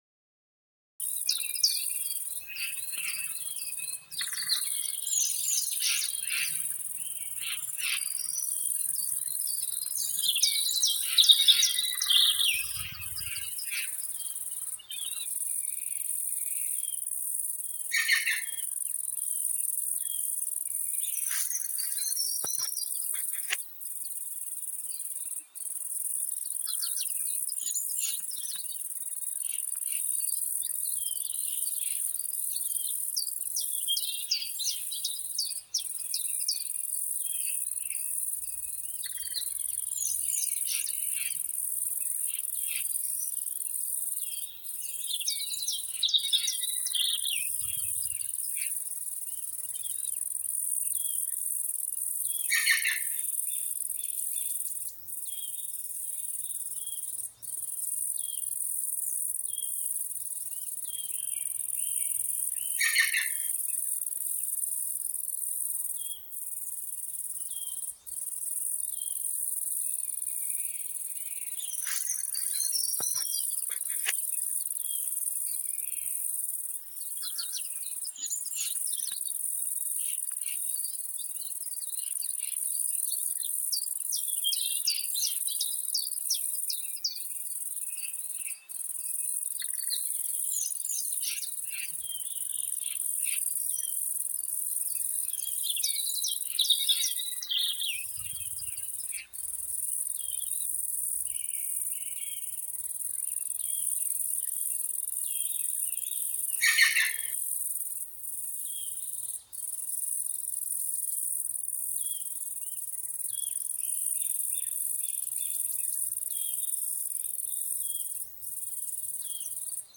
They're all relatively ecologically believable, meaning that you only hear animals that would be found in a same region and same general environment (e.g. a forest at night, a plain during summer...). They are build with an audio software and are not original recordings though.
European forest in the spring".
european_forest_spring.ogg